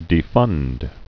(dē-fŭnd)